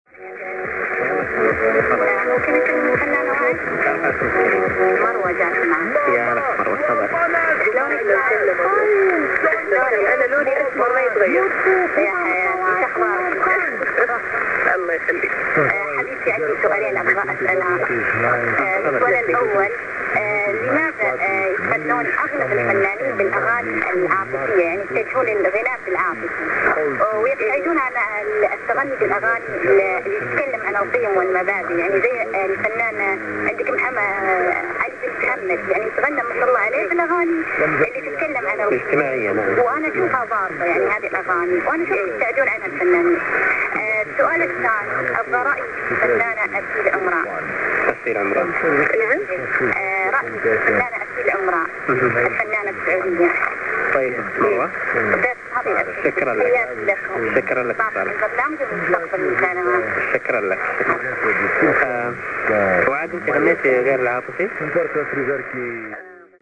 Emissão Provincial do Niassa from Lichinga, Mozambique on 1260 kHz. Their high energy Friday night music programme was a perfect end to this wonderful DX season.